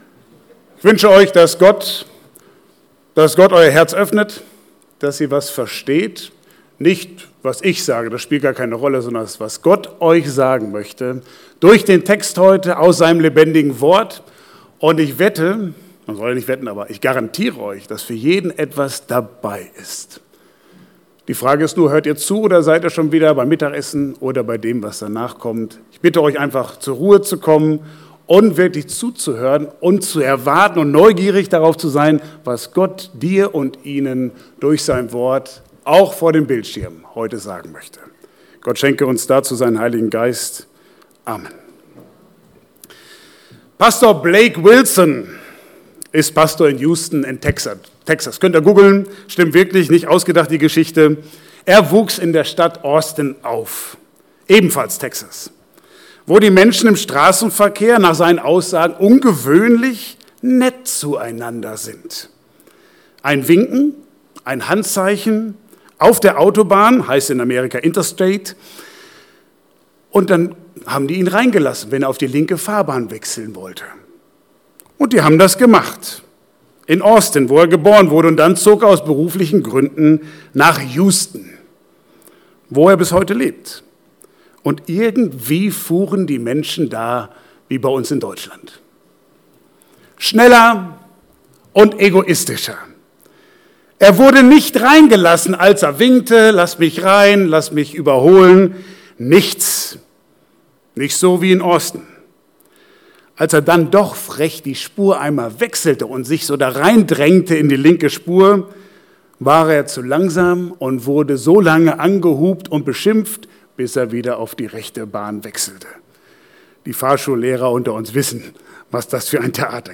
Passage: Johannes 8, 3-11 Dienstart: Gottesdienst « Gott versorgt dich!
predigt-0710.mp3